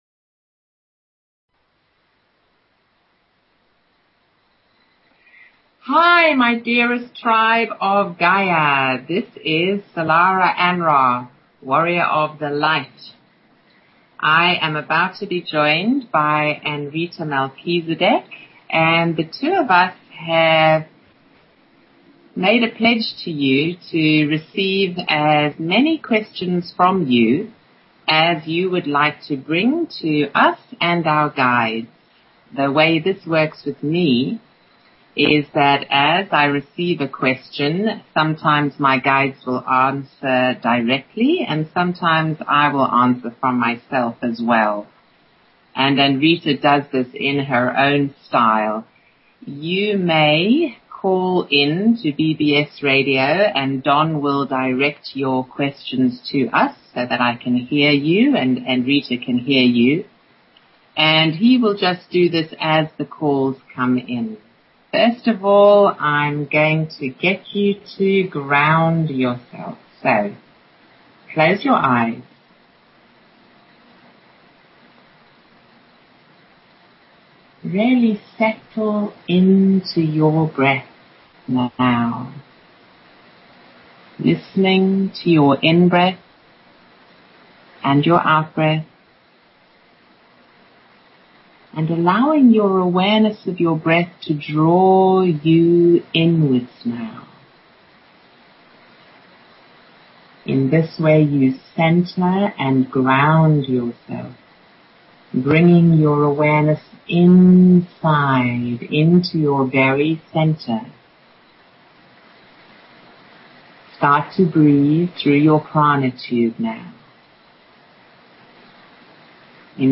Talk Show Episode, Audio Podcast, Illumination_from_the_Councils_of_Light and Courtesy of BBS Radio on , show guests , about , categorized as